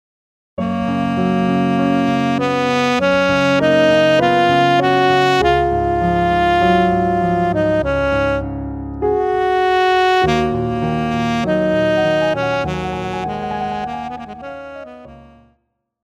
古典
钢琴
独奏与伴奏
有主奏
有节拍器